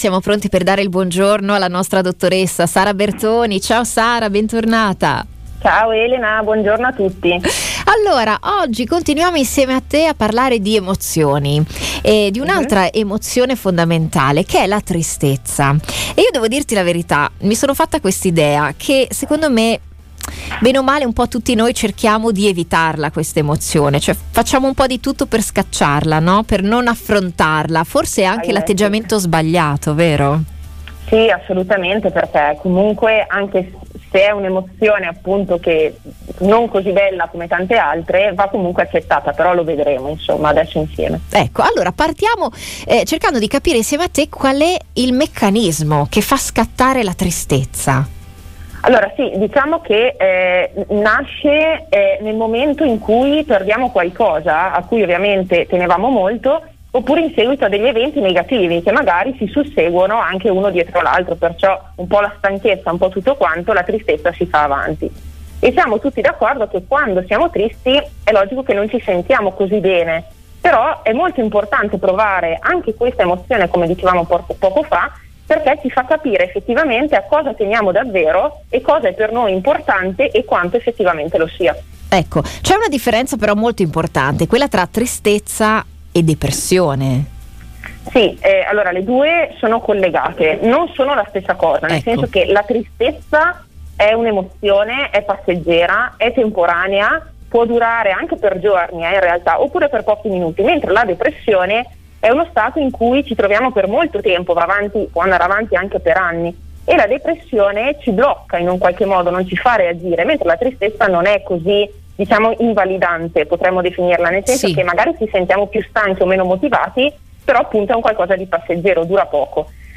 Nell’intervista all’esperta scoprirete anche da cosa la tristezza vada distinta e in che modo: